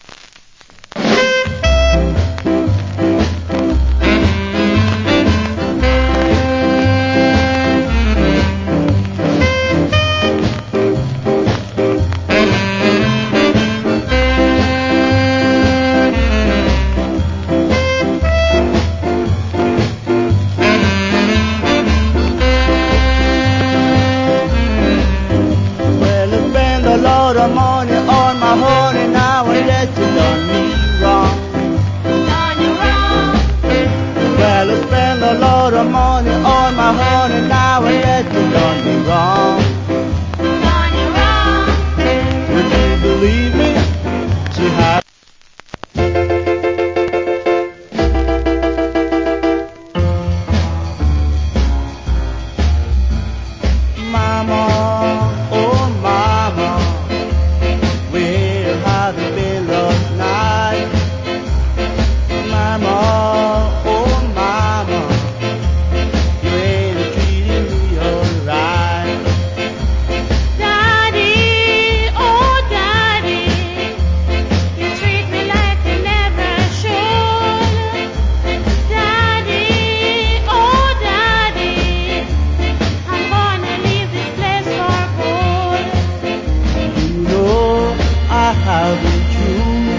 Nice Ska Vocal.